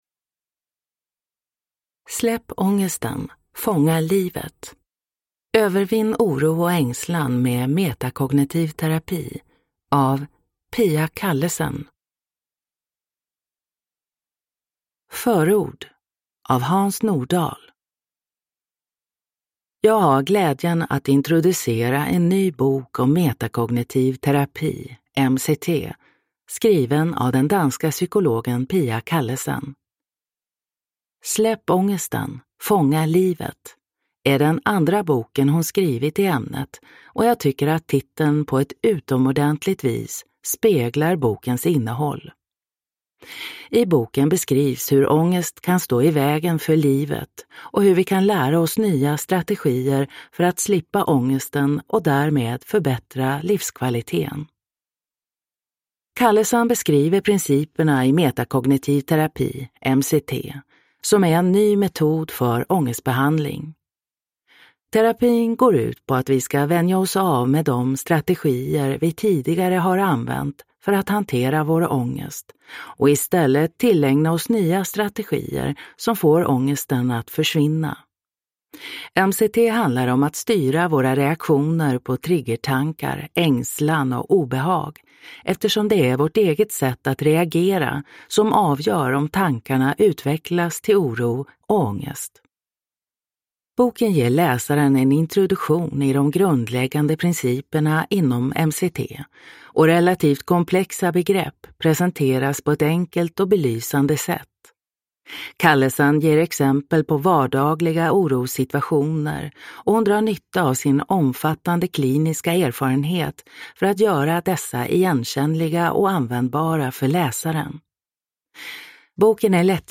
Släpp ångesten, fånga livet : övervinn oro och ängslan med metakognitiv terapi – Ljudbok